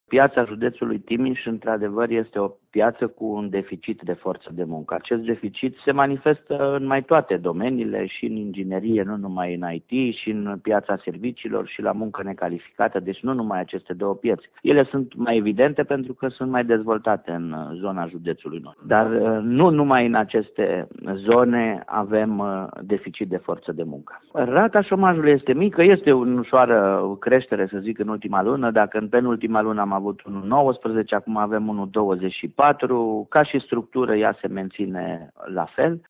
Agenţia Judeţeană pentru Ocuparea Forţei de Muncă din Timiş atrage atenţia că lipsa forţei de muncă se întâlneşte şi în alte sectoare, spune directorul AJOFM, Marcel Miclău.